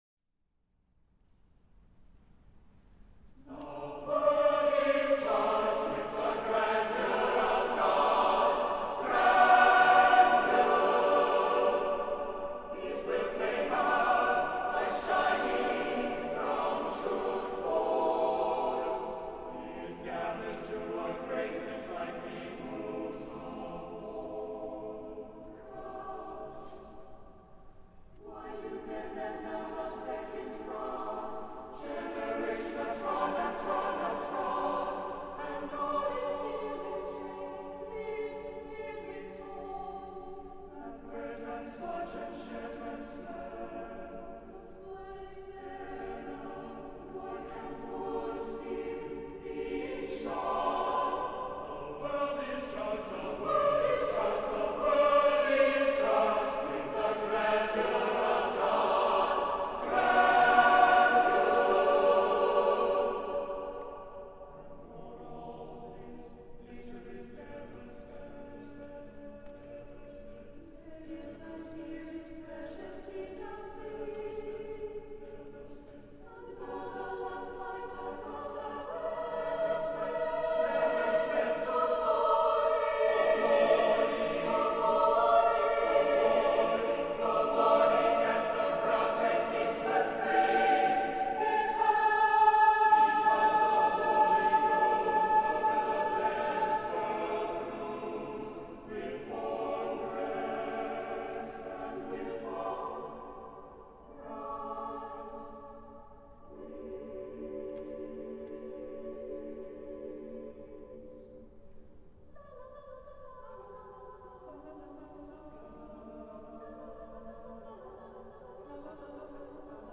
for SATB Chorus (2002)
The rhythms are fluid.
As in the opening song, this is triumphant music.